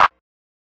low pass clap.wav